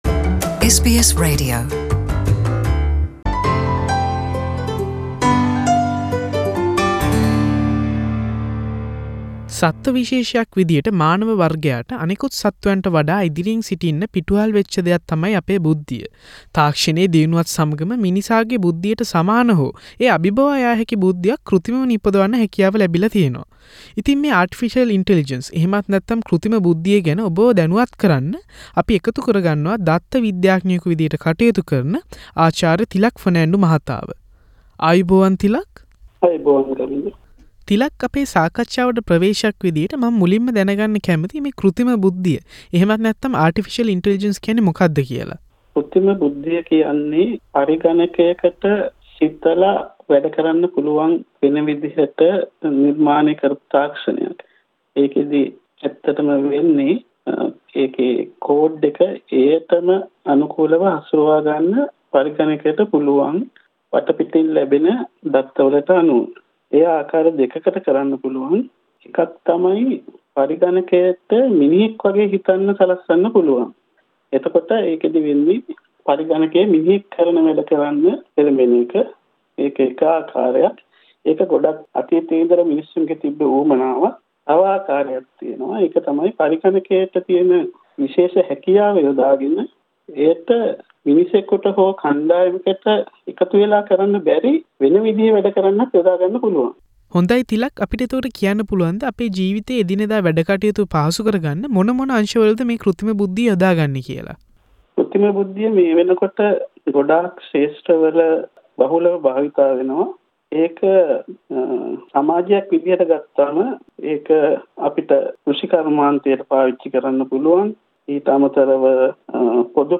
සාකච්චාව